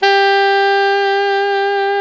Index of /90_sSampleCDs/Roland L-CDX-03 Disk 1/SAX_Alto Tube/SAX_Alto mp Tube
SAX ALTOMP0C.wav